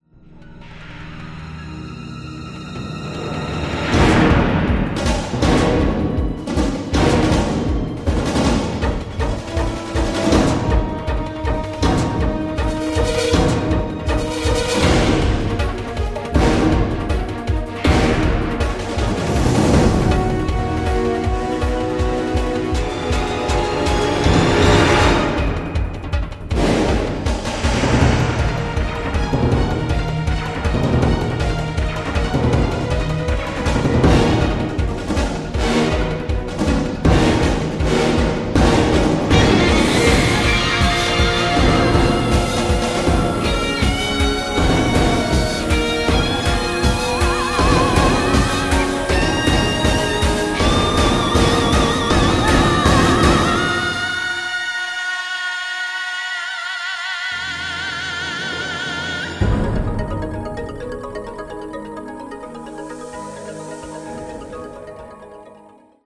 original motion picture score